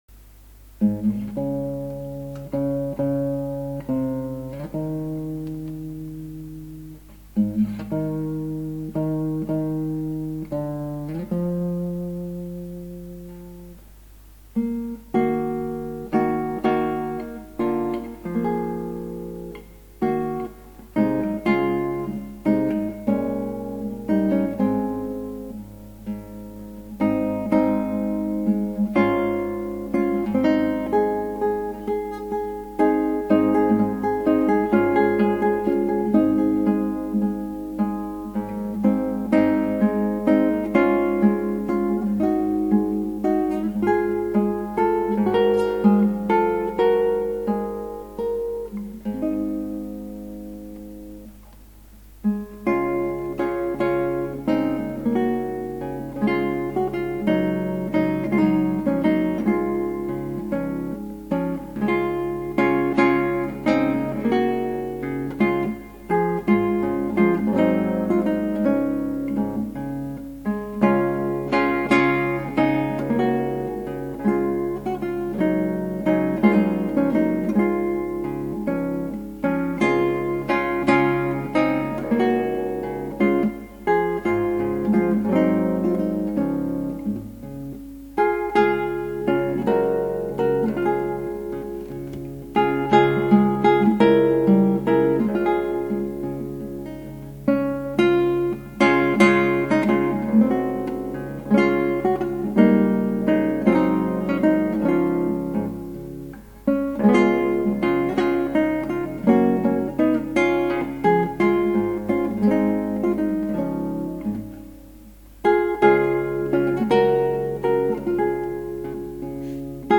クラシックギター　ストリーミング　コンサート
しょうがないんで序奏とテーマだけ弾いて、お尻に最後の部分つけてお茶を濁すと・・・。